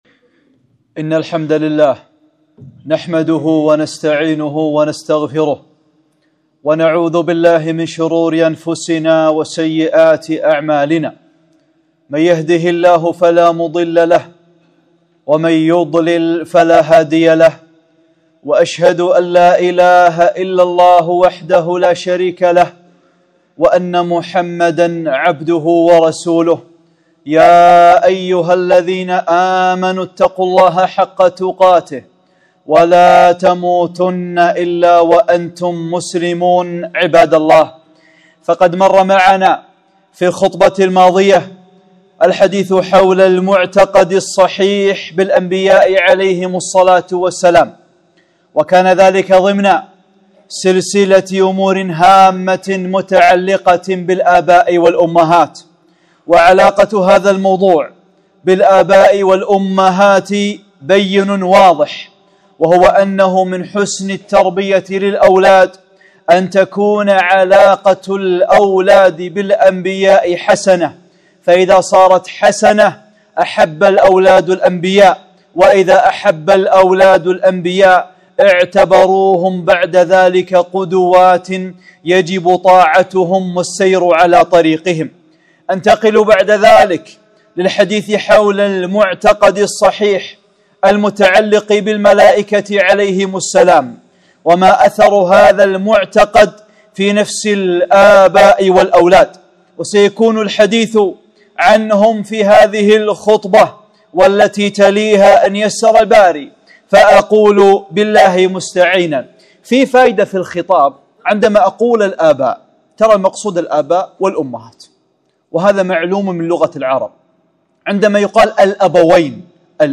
(38) خطبة - وجوب الإيمان بالملائكة، أسماء الملائكة